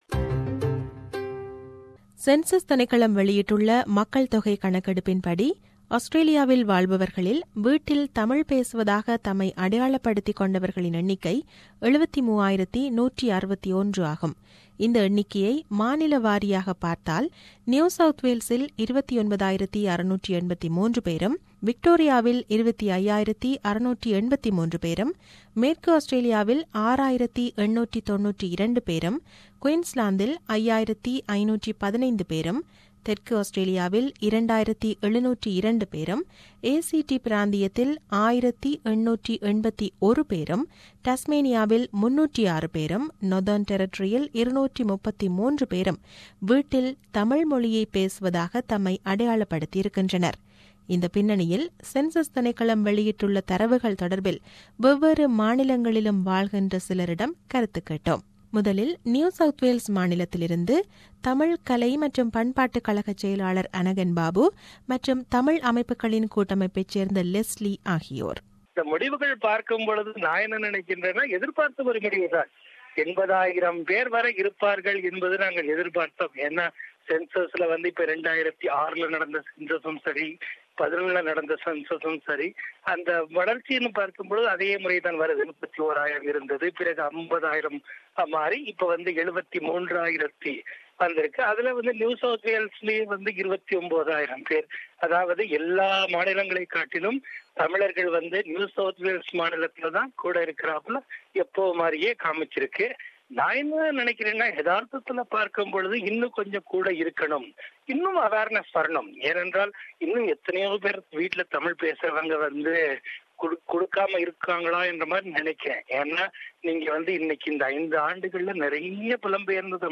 Voxpop on Census 2016